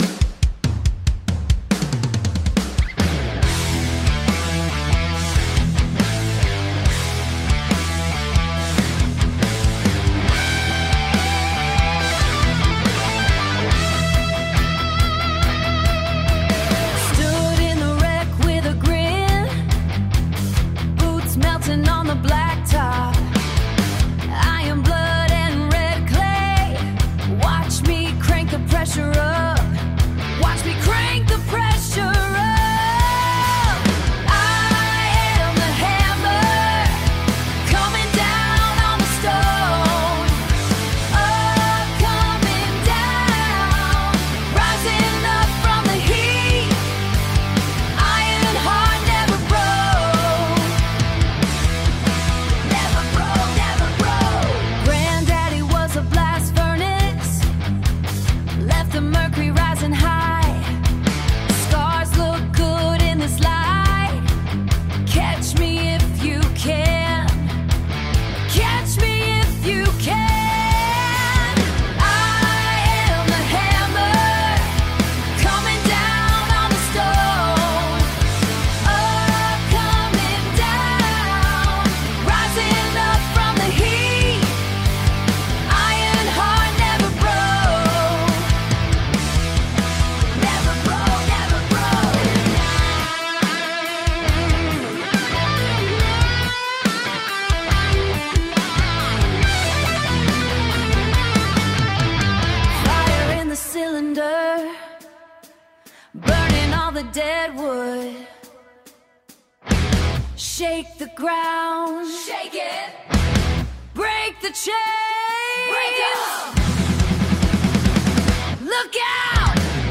90 BPM • Cinematic Rap
Cinematic Orchestral Hip-Hop / East Coast Funk-Bap